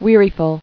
[wea·ri·ful]